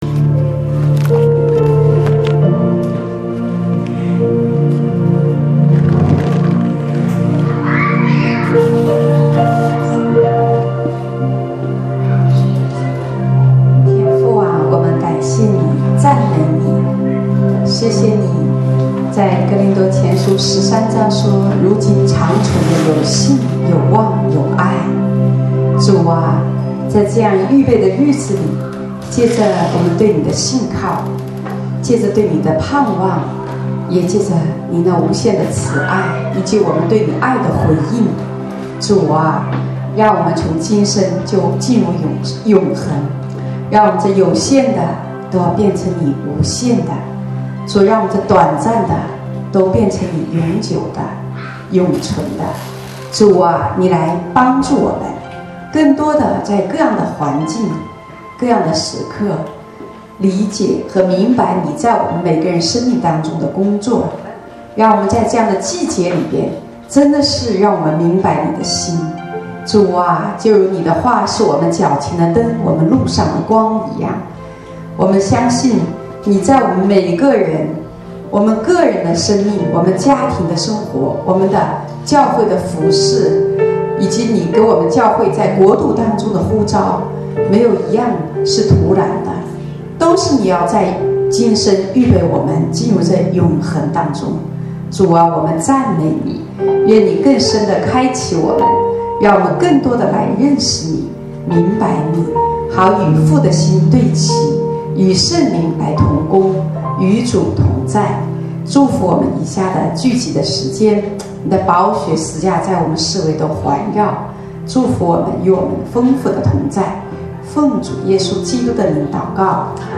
主日恩膏聚会